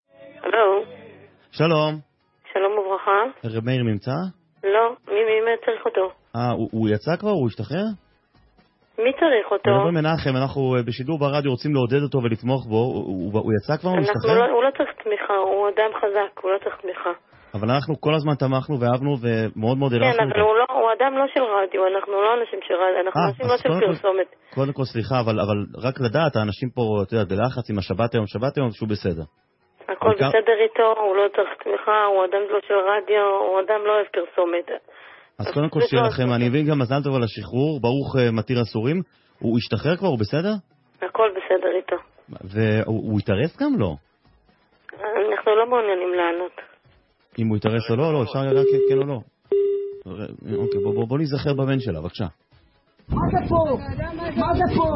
והיא בעדינות נפשה מסבירה לו שהיא לא מעוניינת ותיכף היא מנתקת